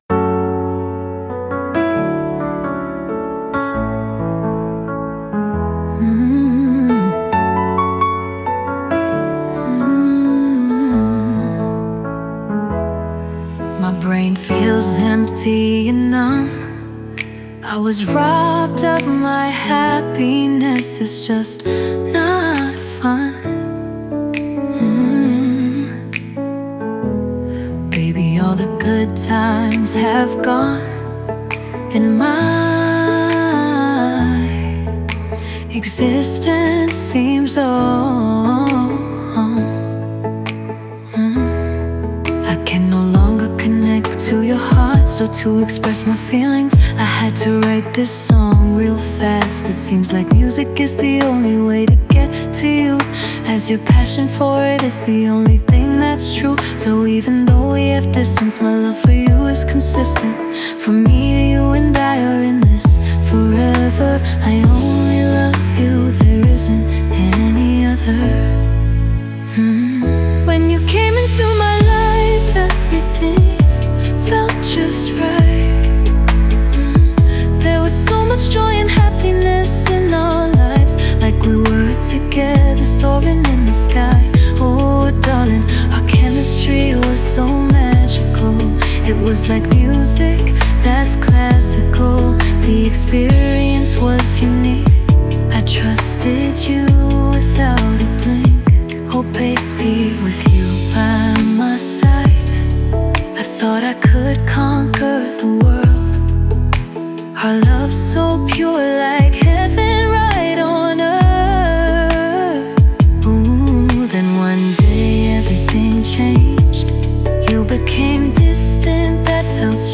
"There Is No Any Other" (pop)